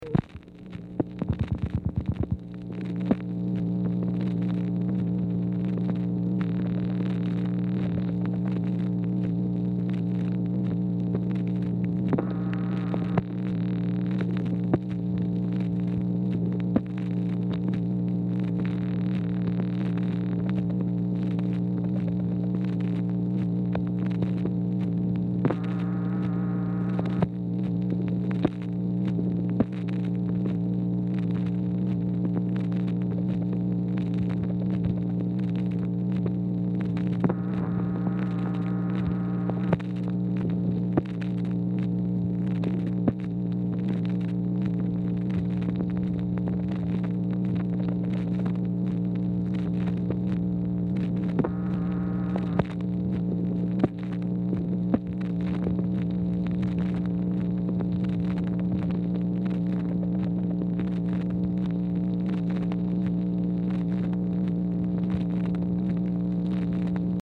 Telephone conversation # 4609, sound recording, MACHINE NOISE, 8/1/1964, time unknown | Discover LBJ
Telephone conversation
Format Dictation belt